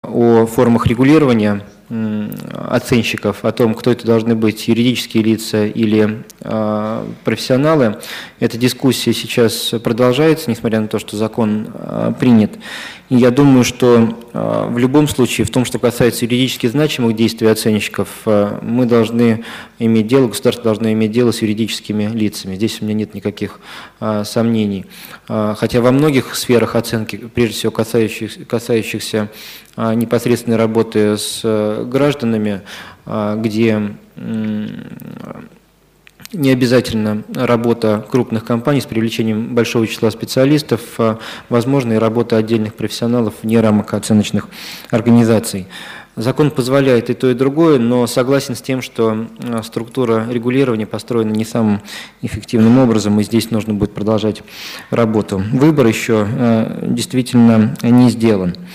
Аудиозапись высказывания Дворковича А.В., по вопросу регулирования оценочной деятельности, на  Международной конференции «Оценка и налогообложение недвижимости: международный опыт и российский выбор»